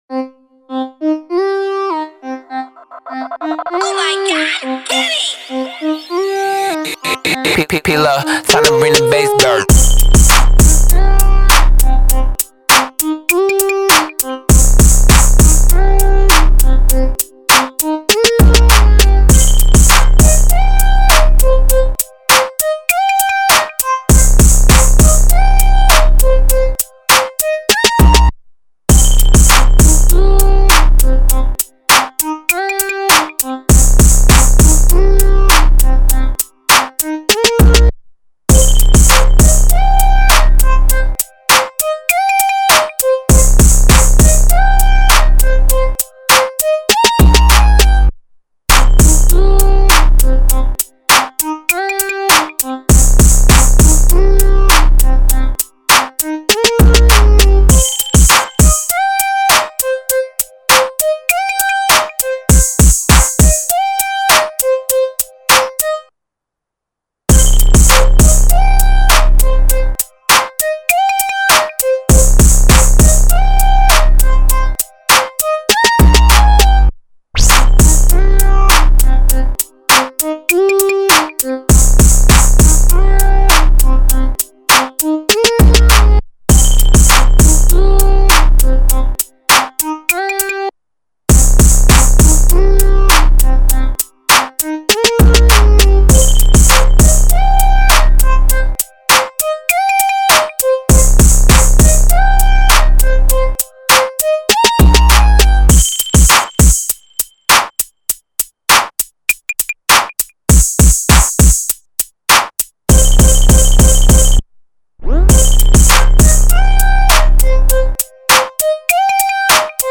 Here's the official instrumental of